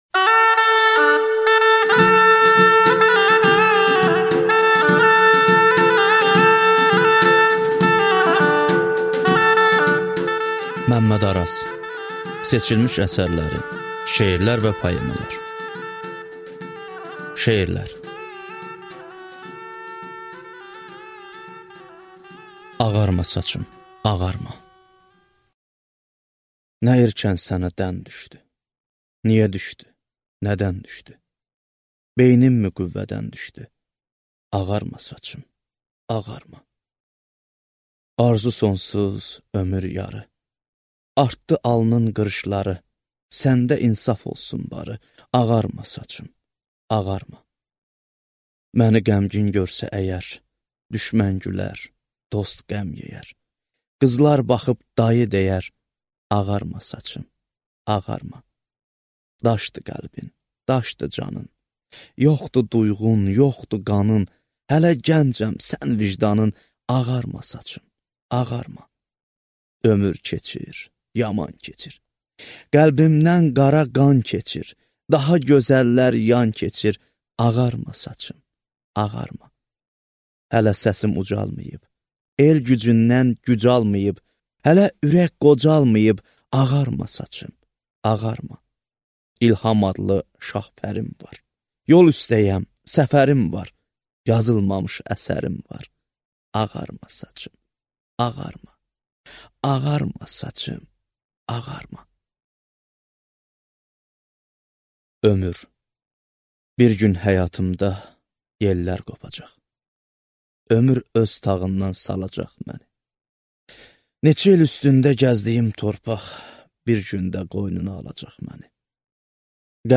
Аудиокнига Seçilmiş əsərləri | Библиотека аудиокниг